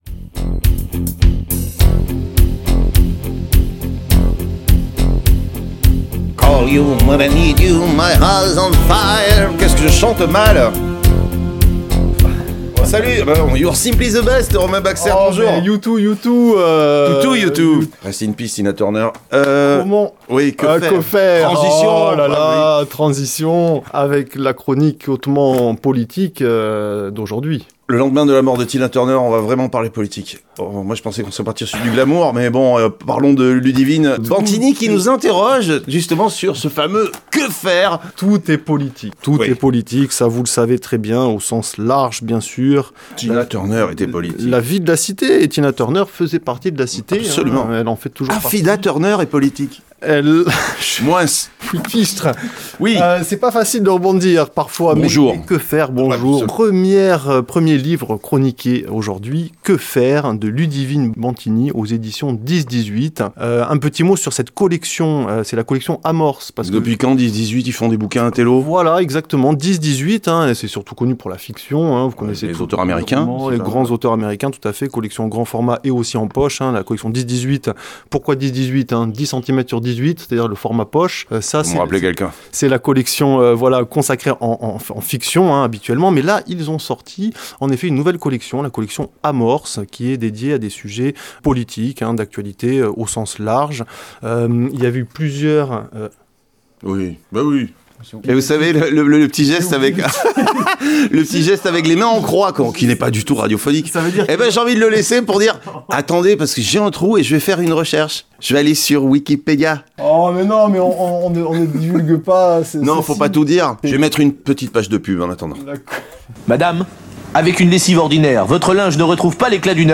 Chronique Littéraire : ” Que faire ?